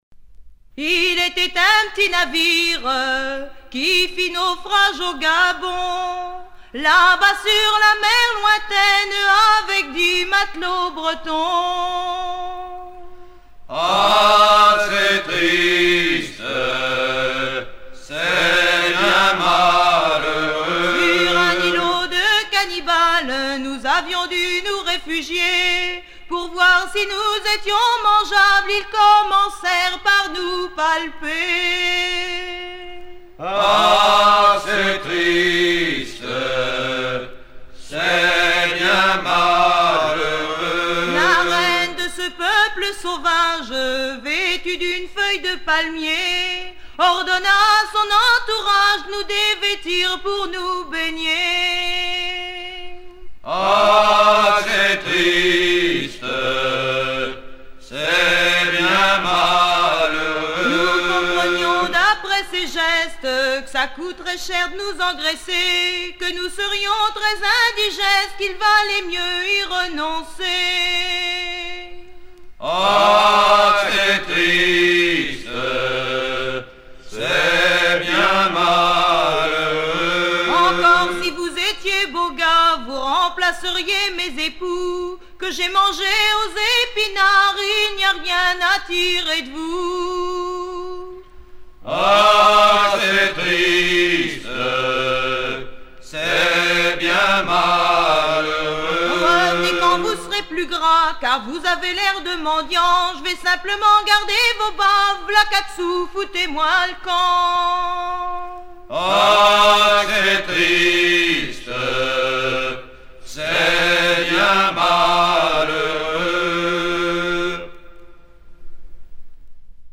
Chants de marins
Pièce musicale éditée